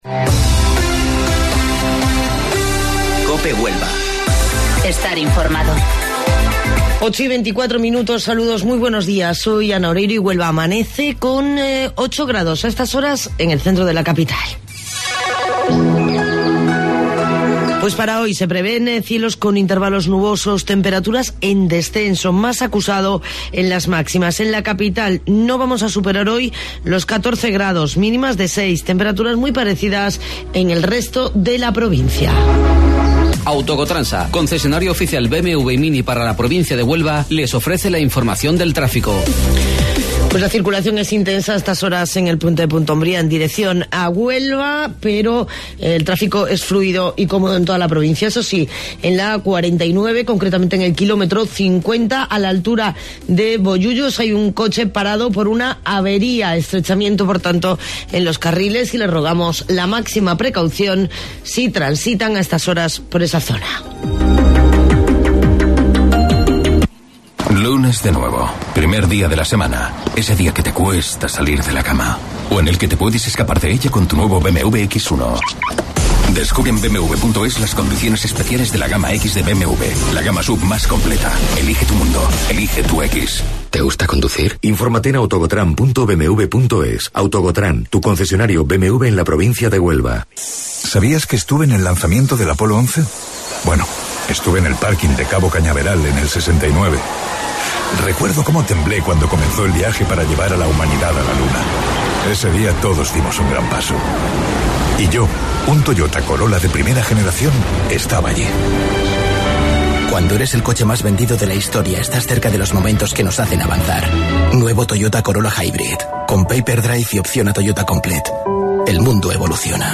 AUDIO: Informativo Local 08:25 del 20 de Enero